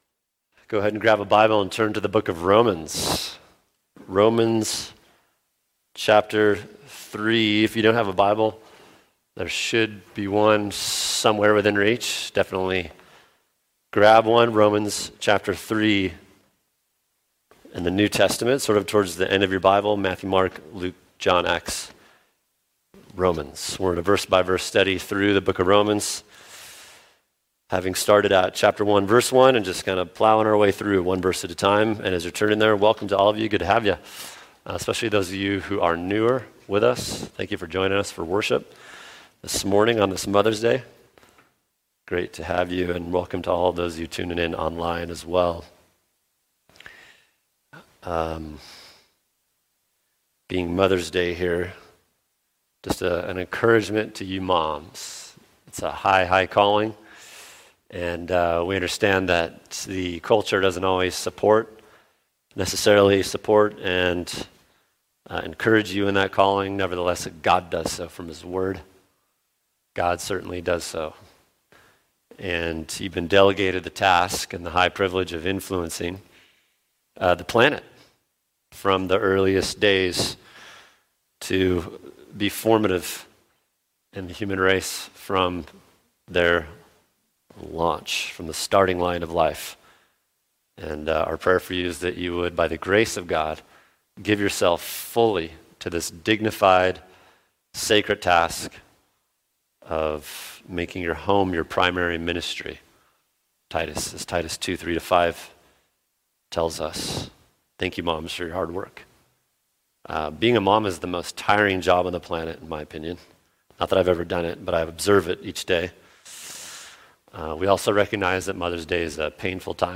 [sermon] Romans 3:22-23 Sola Fide | Cornerstone Church - Jackson Hole